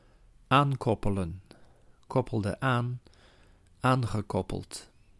Ääntäminen
IPA: /a.kʁɔ.ʃe/